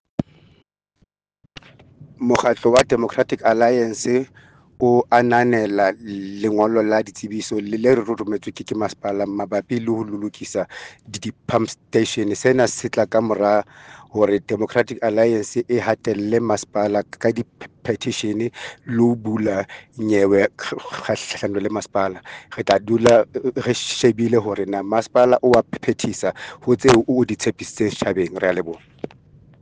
Sesotho soundbites by Cllr Thulani Mbana.